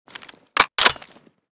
mosin_open.ogg